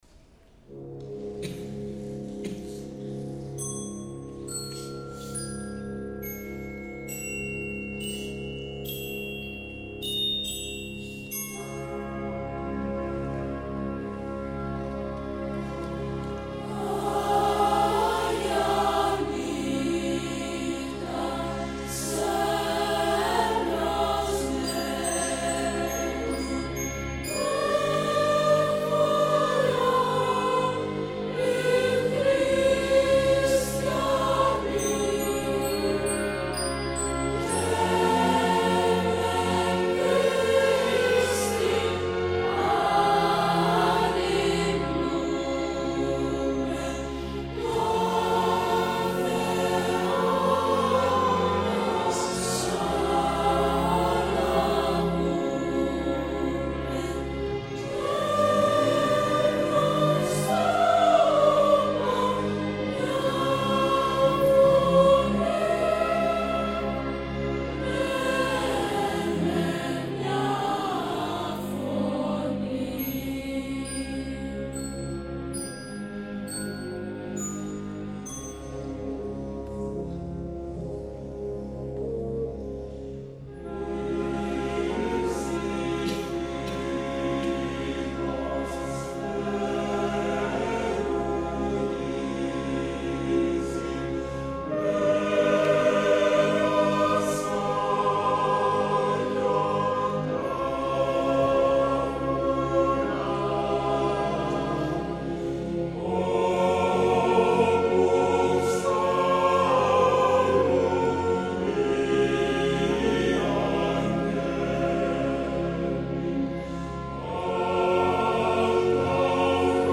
Franz Gruber (Α. Μπαλτάς) – “Άγια Νύχτα” για Χορωδία και Ορχήστρα
Χορωδίες : “Επιλογή” και “Πολυφωνία”
Συμφωνική Ορχήστρα Κύπρου